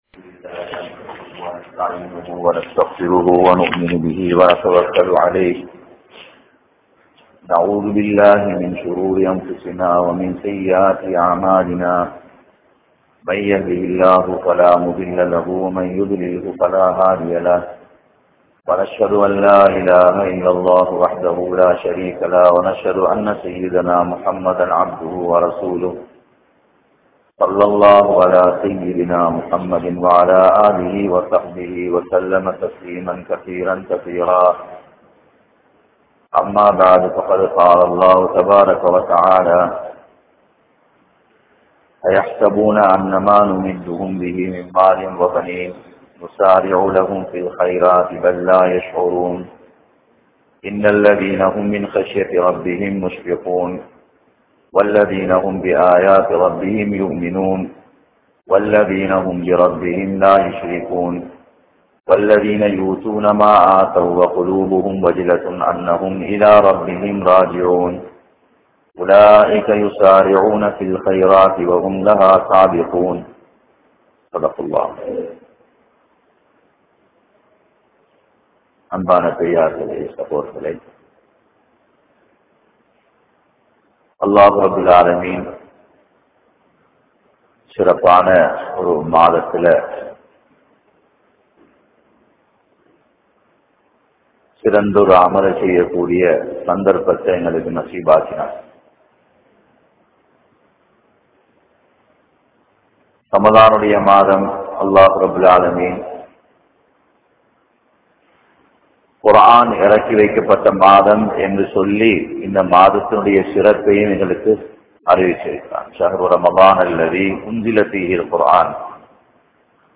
Arivillaatha Manitharhal (அறிவில்லாத மனிதர்கள்) | Audio Bayans | All Ceylon Muslim Youth Community | Addalaichenai